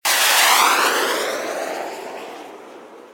.火箭发射.ogg